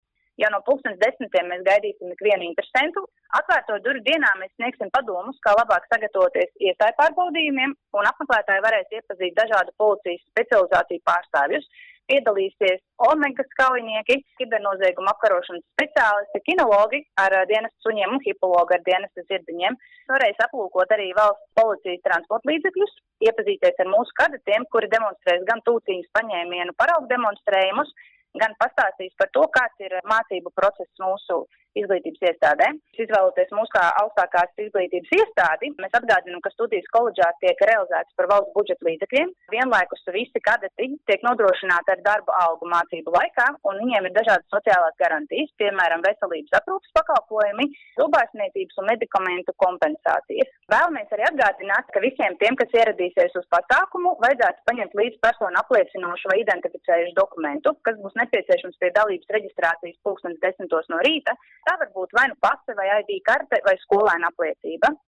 RADIO SKONTO Ziņās par Atvērto durvju dienu Valsts policijas koledžā